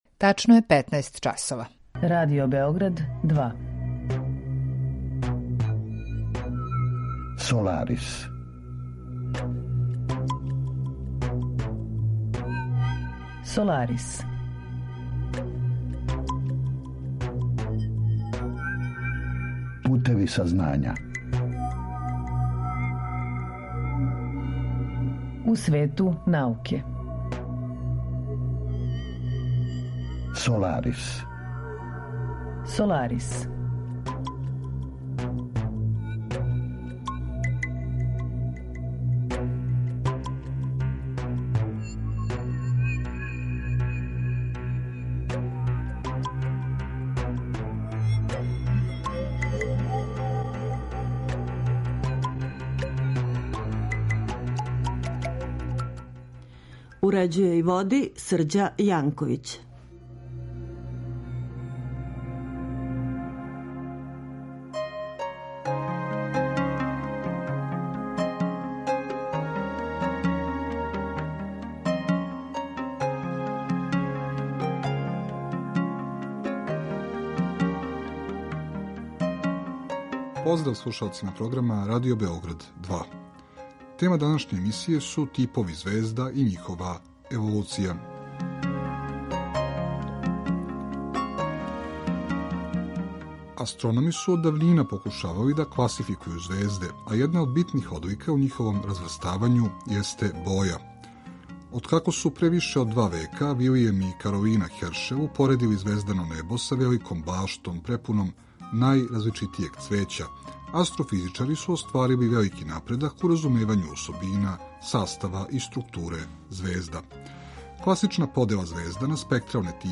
Саговорница